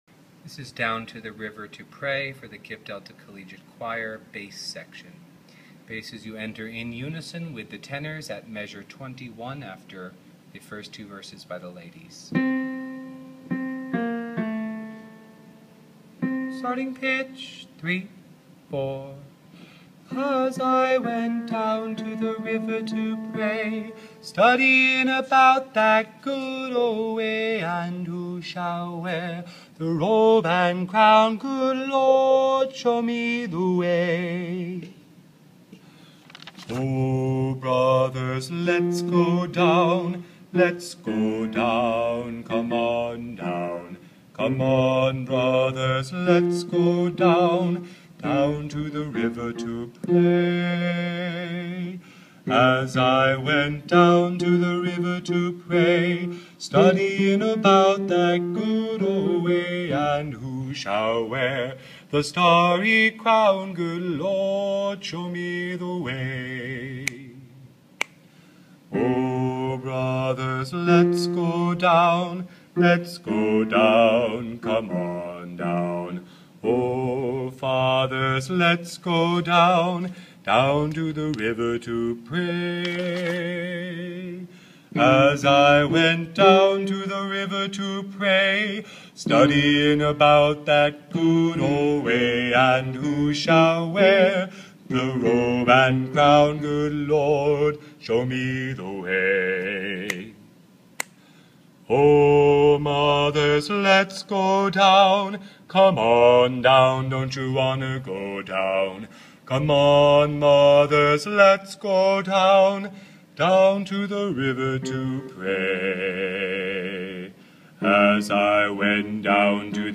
Down to the river to pray – Bass